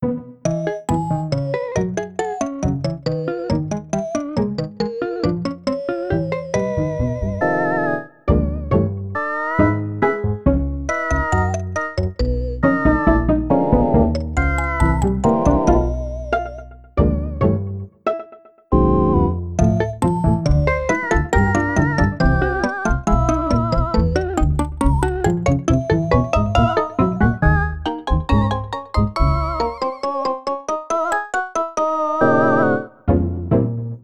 The final version of the song, with preemphasis enabled and echo delay back on (notably a higher echo setting than the SNESMOD examples used, meaning it sounds more reverberous).